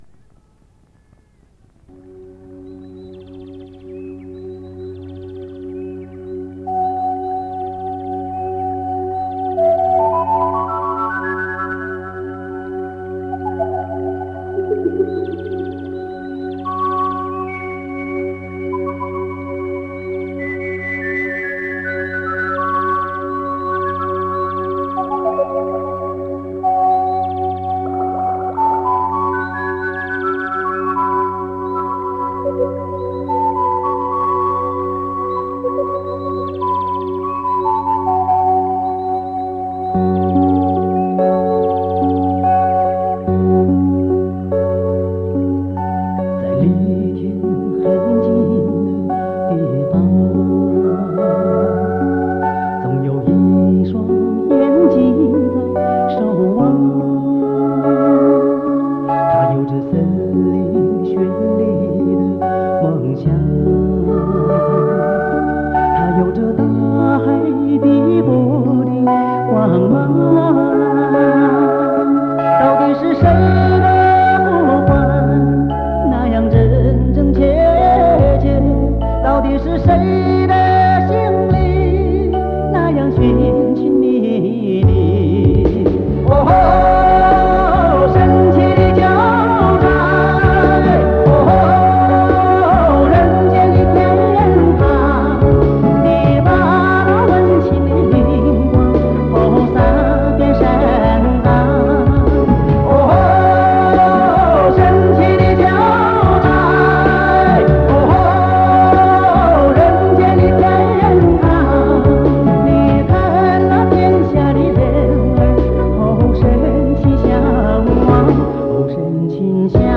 录音室：中国唱片社成都分社
藏族歌手
每一首歌的旋律都非常美妙，充满了民族风情